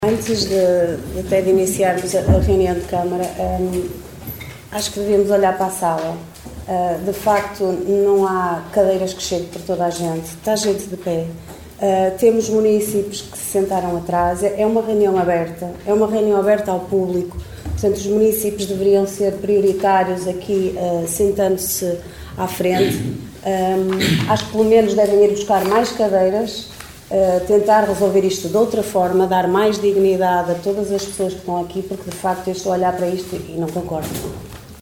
Excertos da última reunião do executivo caminhense, no passado dia 6 de Novembro no Salão Nobre do edíficio dos Paços do Concelho.